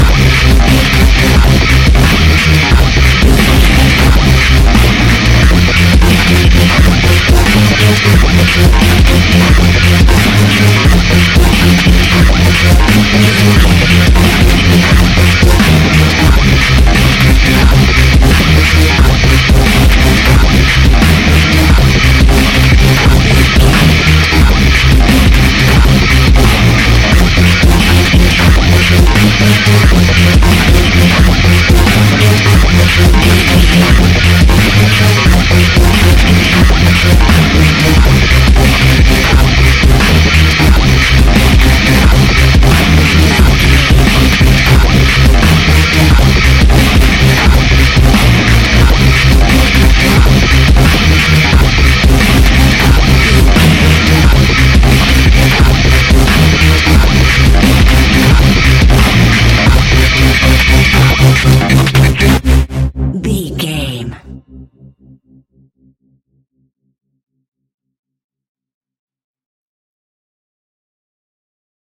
Epic / Action
Fast paced
Aeolian/Minor
aggressive
dark
intense
disturbing
synthesiser
drum machine
breakbeat
energetic
synth leads
synth bass